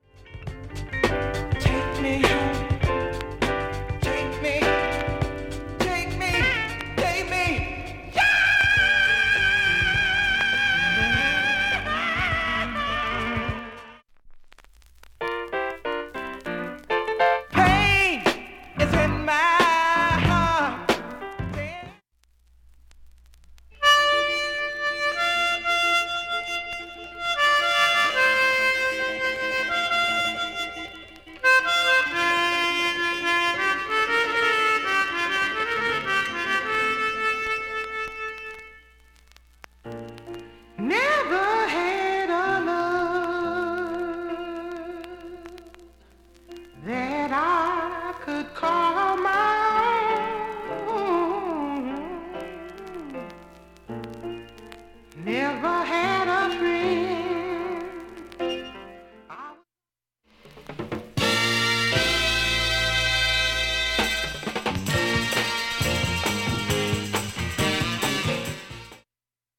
音質良好全曲試聴済み。
A-1後半にかすかなプツが５回出ます。
３回までのかすかなプツが３箇所